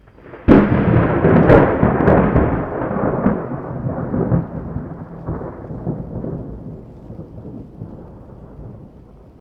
thunder-6.ogg